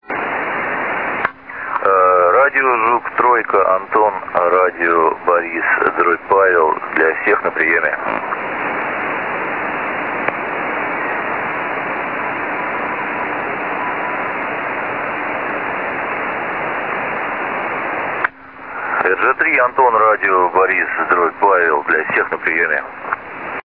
даёт общий вызов